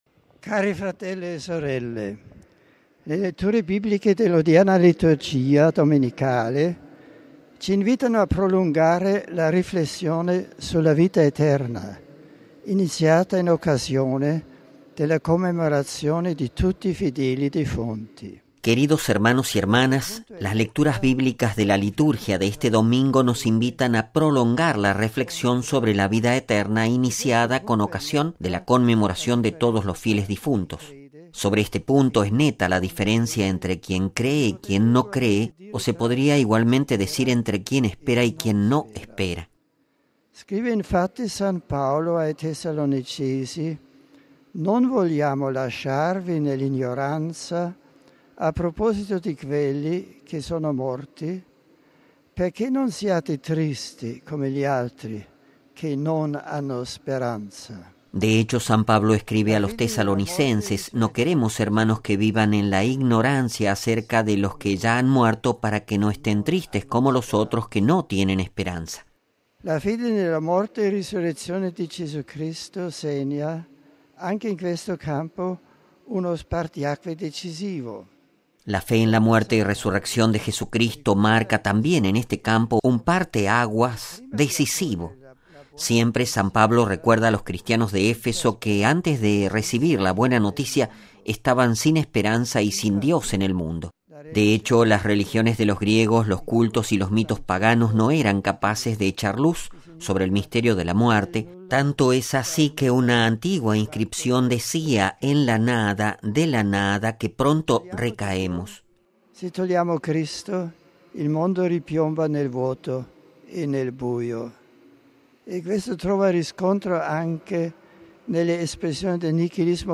Texto completo de la reflexión del Papa, previa a la oración del Ángelus: